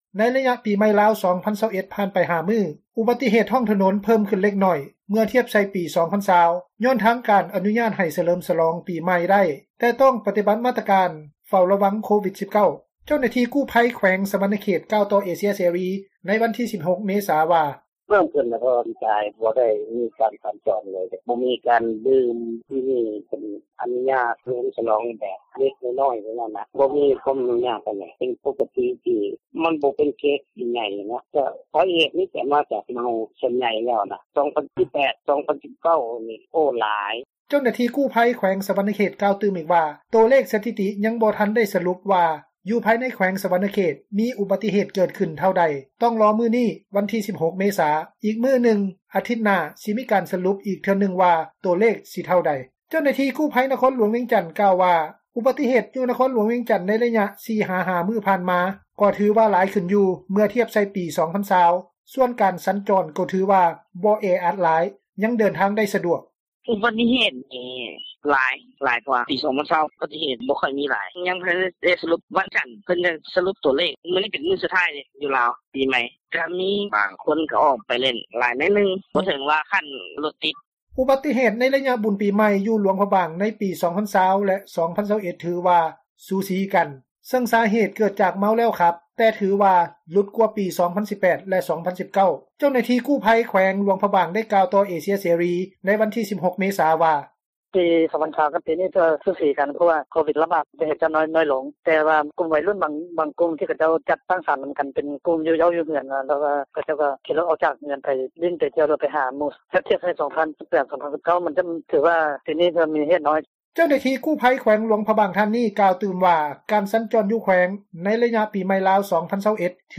ເຈົ້າໜ້າທີ່ ກູ້ພັຍ ຢູ່ແຂວງຫຼວງພຣະບາງ ໄດ້ກ່າວຕໍ່ເອເຊັຽເສຣີ ໃນວັນທີ 16 ເມສາ ວ່າ:
ການສັນຈອນໄປມາໃນໄລຍະປີໃໝ່ 2021 ຖືວ່າສະດວກສະບາຍ ບໍ່ແອອັດຫລາຍ. ຊາວນະຄອນຫລວງວຽງຈັນ ໄດ້ກ່າວຕໍ່ເອເຊັຽ ເສຣີ ໃນວັນທີ 16 ເມສາ ວ່າ:
ຊາວບ້ານອີກທ່ານນຶ່ງ ຢູ່ແຂວງສວັນນະເຂດ ໄດ້ກ່າວຕໍ່ເອເຊັຽເສຣີ ວ່າ ອຸບັດຕິເຫດ ໃນແຂວງສວັນນະເຂດ ໃນໄລຍະປີໃໝ່ ປີນີ້ ບໍ່ເຫັນຫລາຍປານໃດ.